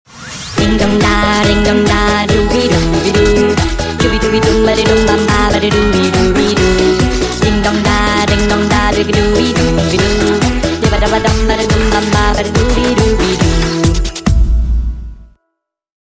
Kategorien: Weihnachten